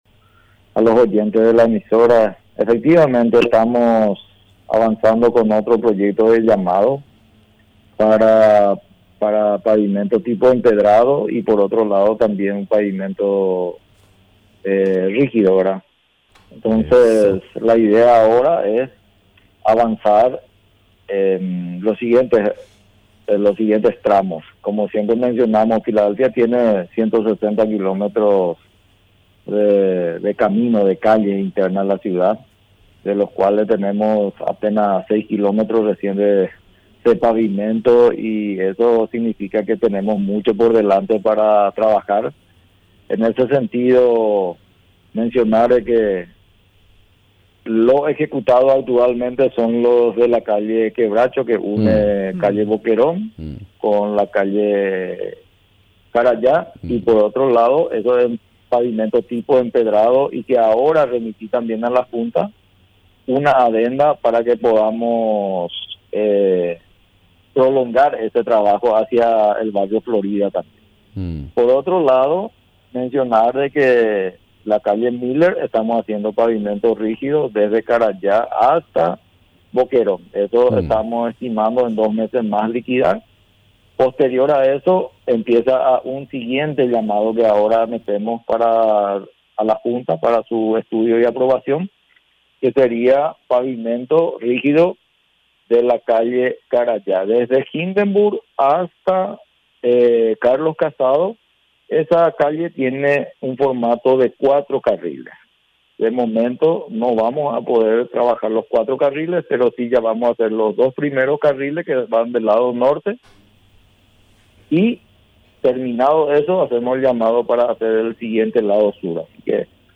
Entrevistas / Matinal 610 Construcción de empedrados Jul 08 2025 | 00:10:07 1x 00:00 / 00:10:07 Subscribe Share RSS Feed Share Link Embed